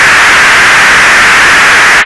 RADIOFX  8-L.wav